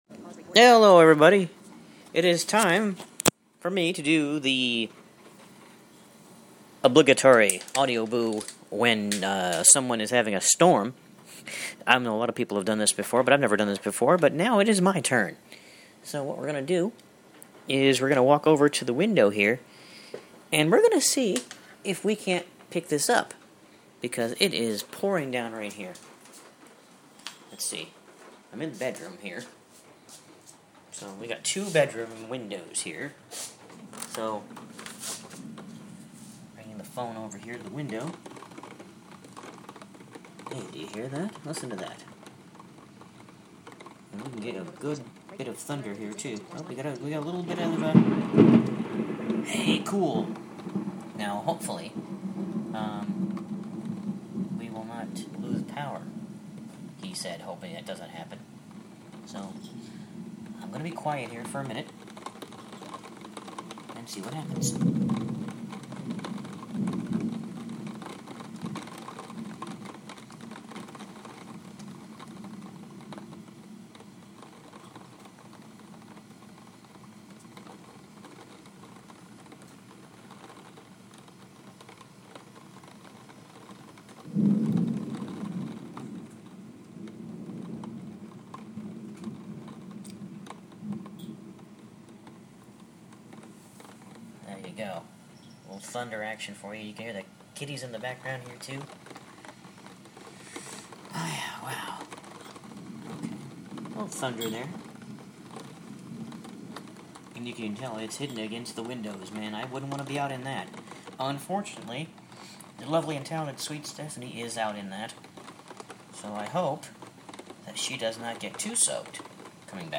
Thunderstorm boo